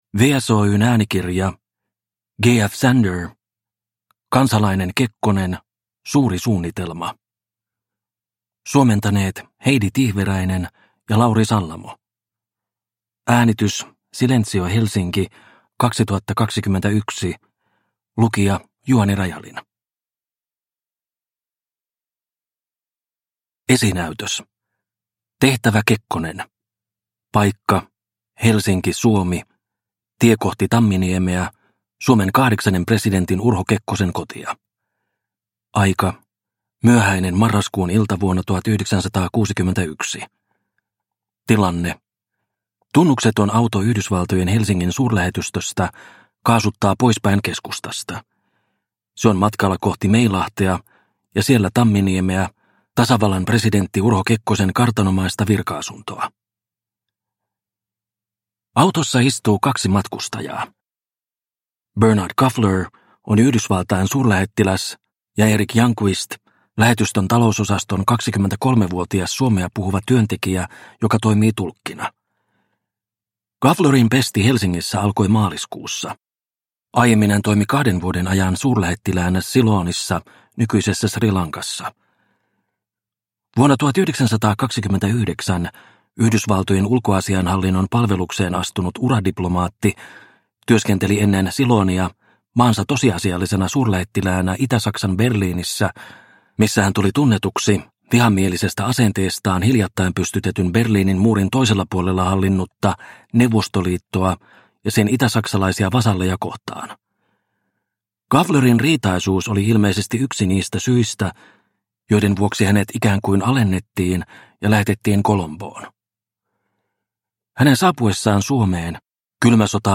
Kansalainen Kekkonen – Ljudbok – Laddas ner